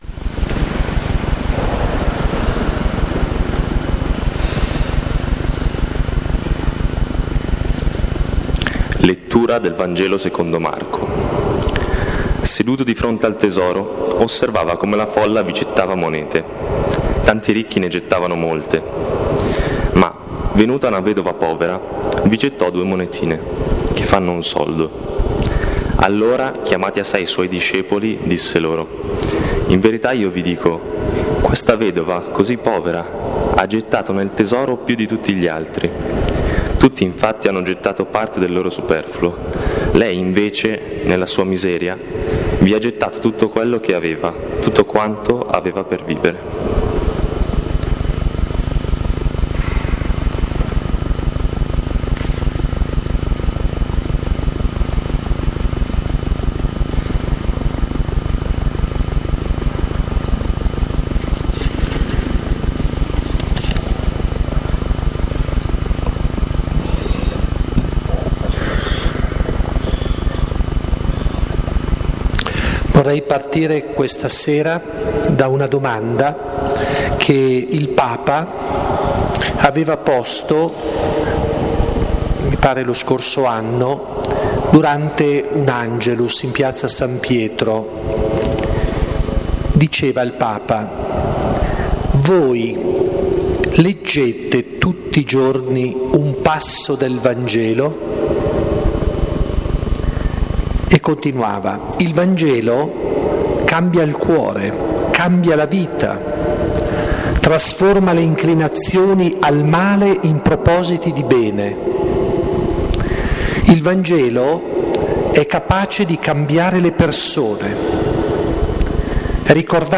5° incontro - Lectio - LO SGUARDO DELLA RADICALITA’ - Comunità Pastorale "San Paolo"